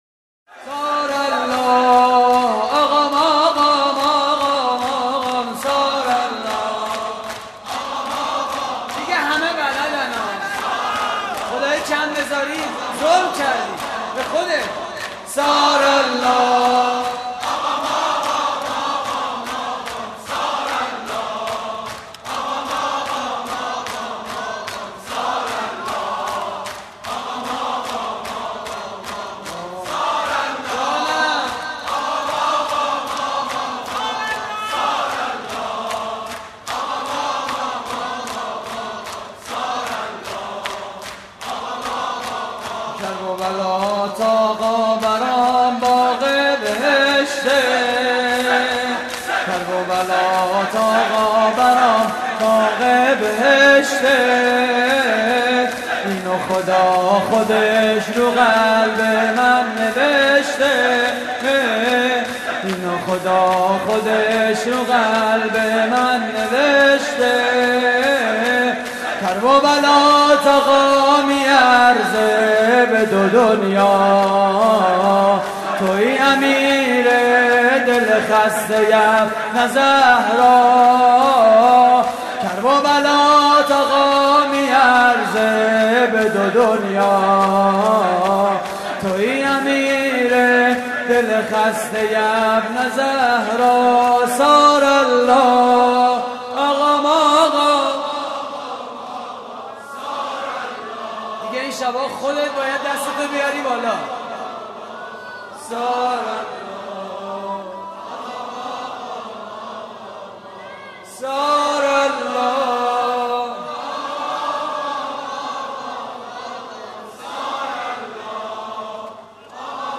شور: کرببلات آقا برام باغ بهشته
مراسم عزاداری شب پنجم ماه محرم